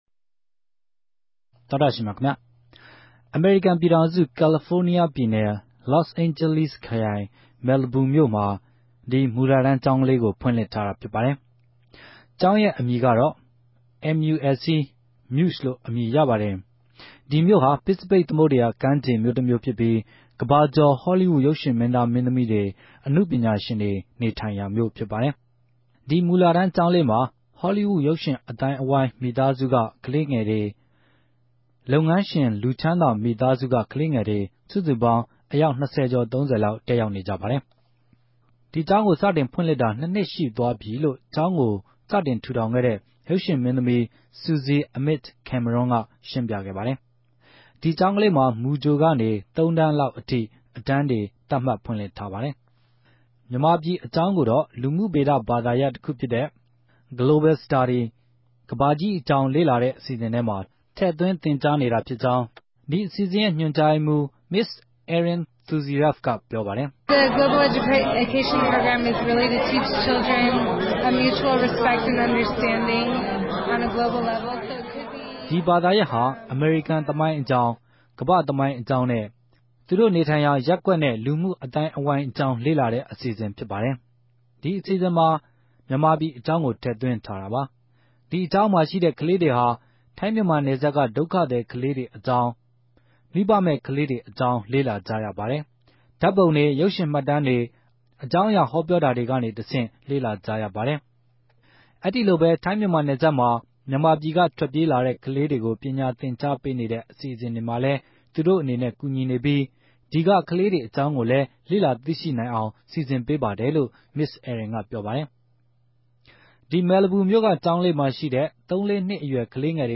တြေႚဆုံမေးူမန်းထားတာကို